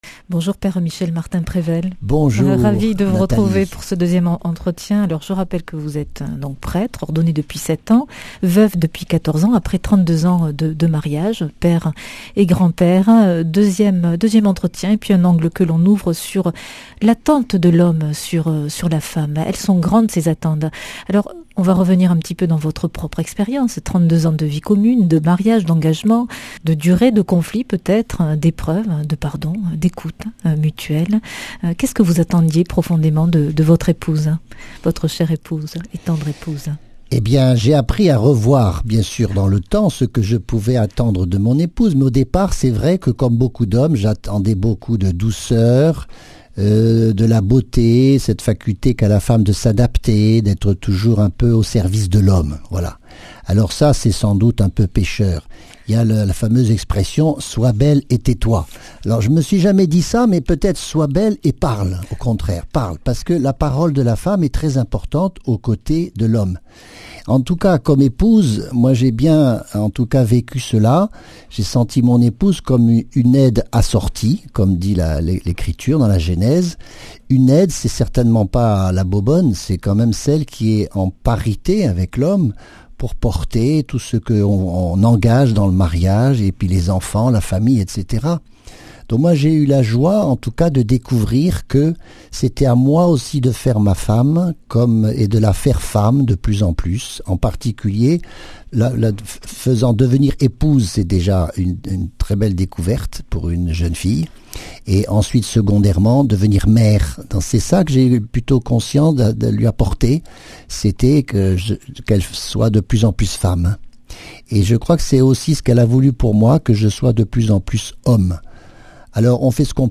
Speech
Une émission présentée par